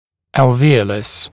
[ælvə’ɔləs] [æl’vɪələs] [элвэ’олэс] [эл’виэлэс] альвеола, зубная ячейка (также alveola)